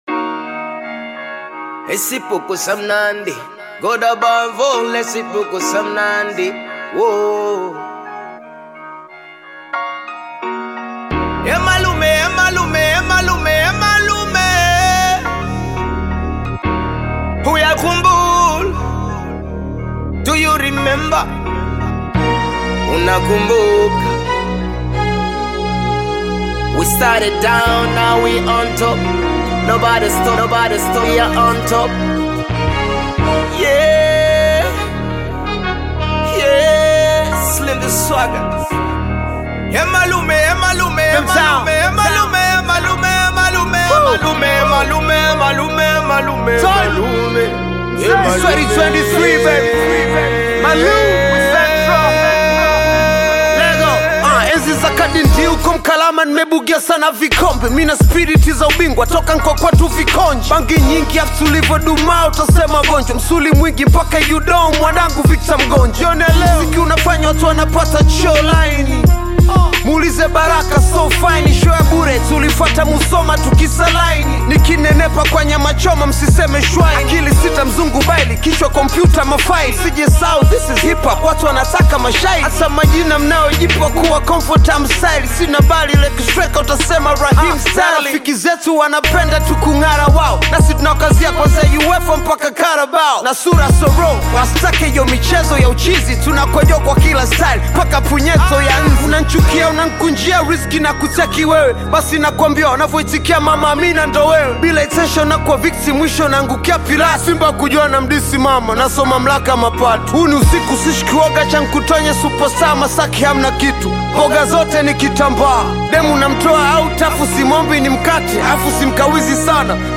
Bongo Flava artist, rapper, singer and songwriter
but it is a hip-hop song that talks about life
African Music